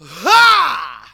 VOX SHORTS-1 0001.wav